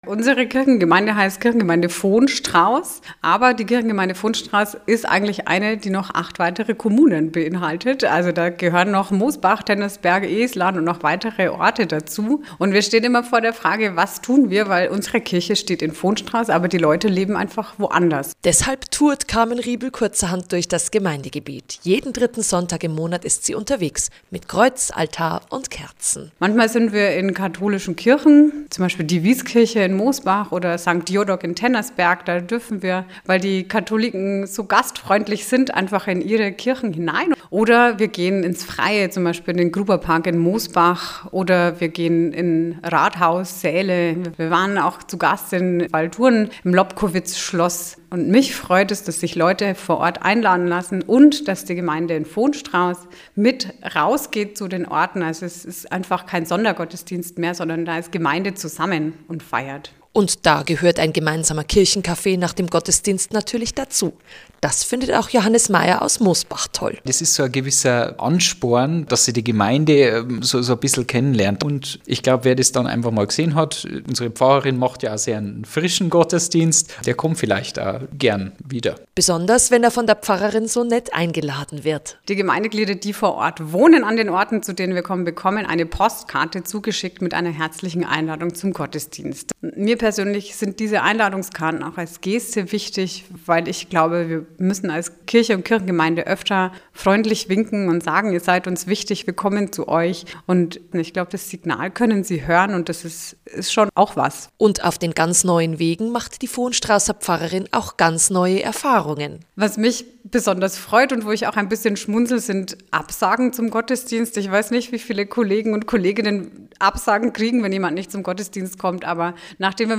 Evangelische Funk-Agentur (efa) Interview "Gemeinde unterwegs"